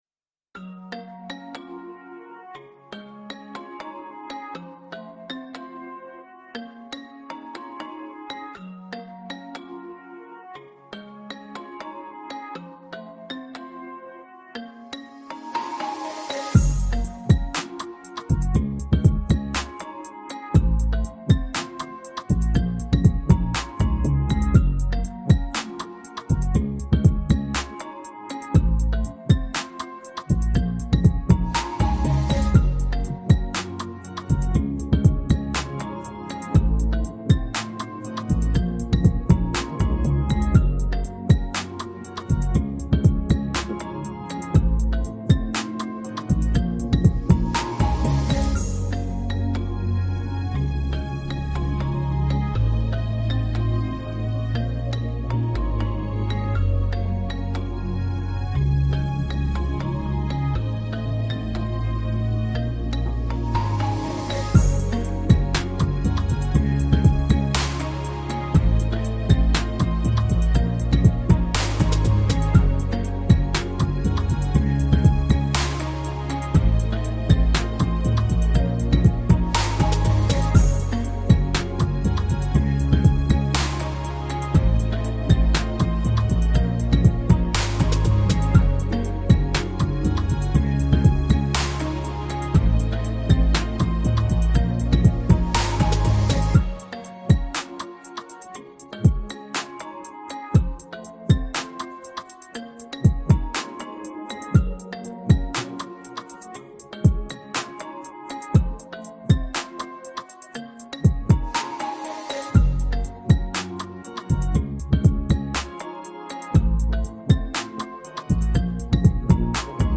Clean Instrumental